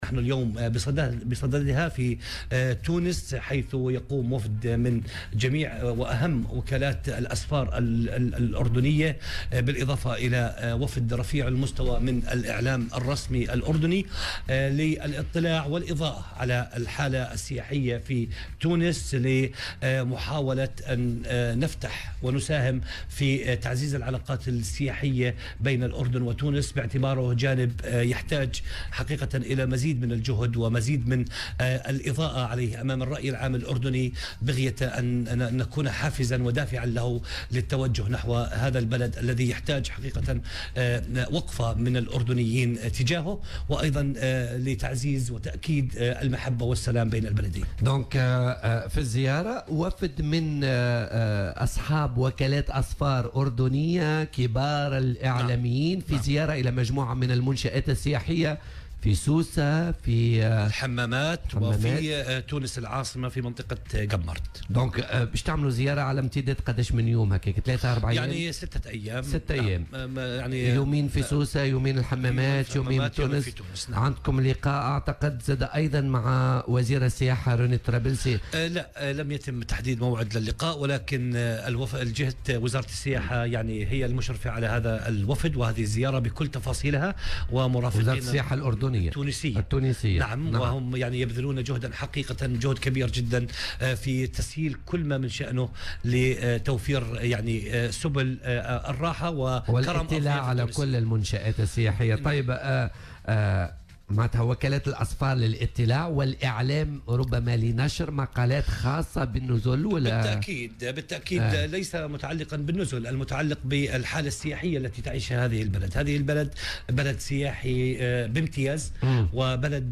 وأضاف في مداخلة له اليوم في برنامج "بوليتيكا" على "الجوهرة أف أم" أن الزيارة، التي ستشمل ولاية سوسة والحمامات وتونس العاصمة، تأتي بالتنسيق مع وزارة السياحة التونسية لمزيد تعزيز العلاقات في المجال السياحي بين البلدين. كما أوضح أيضا أنه سيتم العمل على جلب السياح الأردنيين والتشجيع على الوجهة التونسية، مؤكدا أن تونس بلد آمن ومستقر.